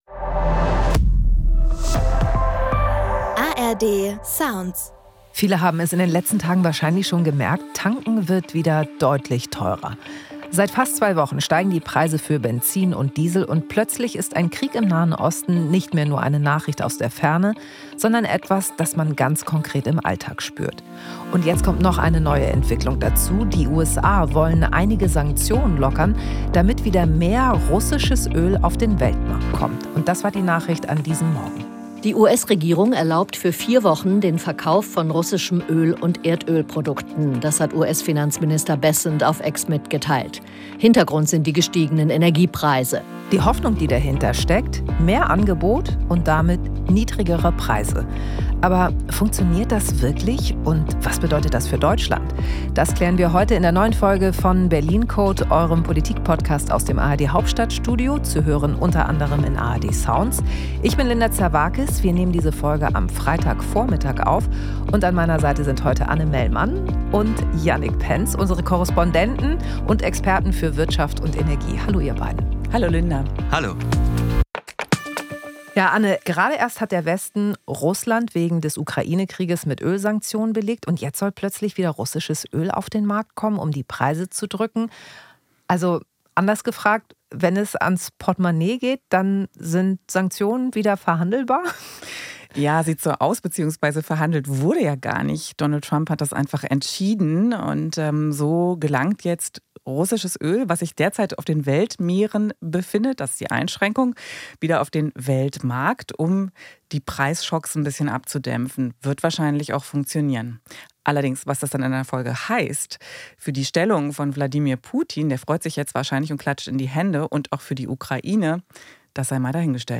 "Berlin Code" ist der Politik-Podcast aus dem ARD-Hauptstadtstudio.